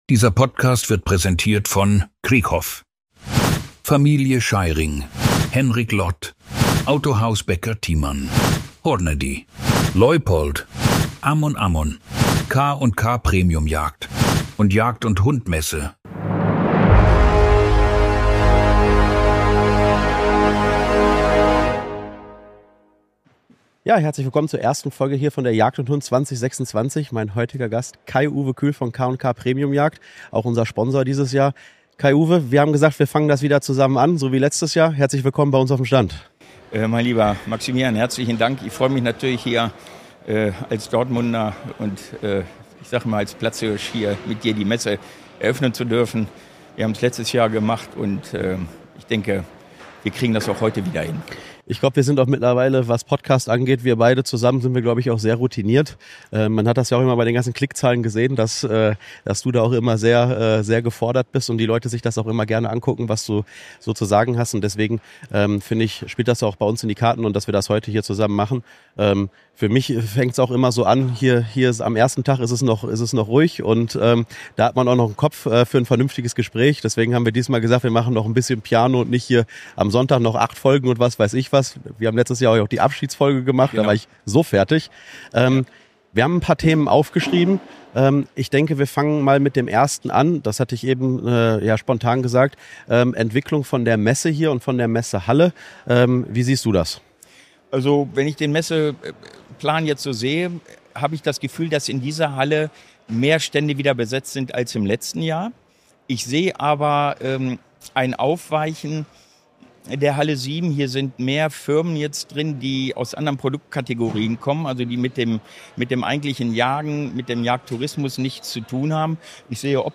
Die beiden heißen alle Besucher herzlich willkommen auf der Messe, sprechen über die Bedeutung persönlicher Gespräche, neue Kontakte und die Freude auf spannende Begegnungen in Dortmund. Zwischen Messestimmung, Jagdleidenschaft und Unternehmergeist geht es um Austausch, Zusammenarbeit und natürlich um gute Gespräche und erfolgreiche Geschäfte. Der perfekte Start in eine besondere Podcast-Reihe direkt von einer der wichtigsten Jagdmessen Europas.